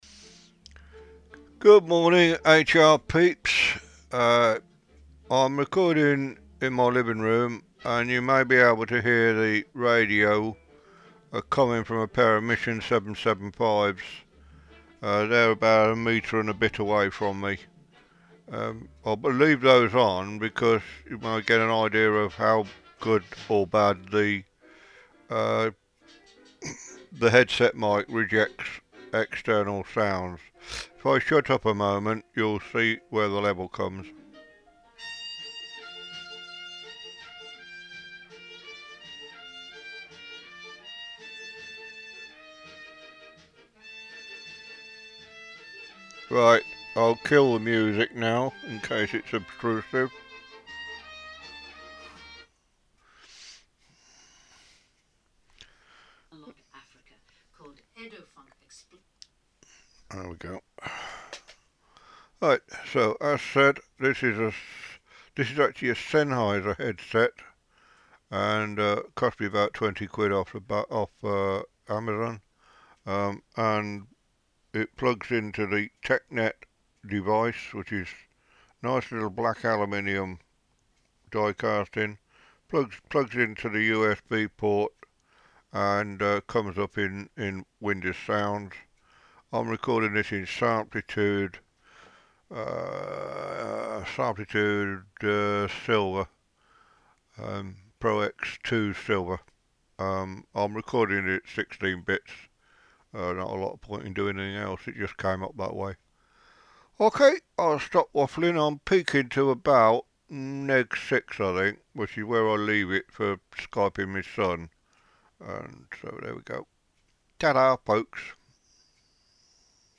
The headset is the Sennheiser PC3 CHAT £17.99. If you have two jacks on your laptop you don't need the dongle.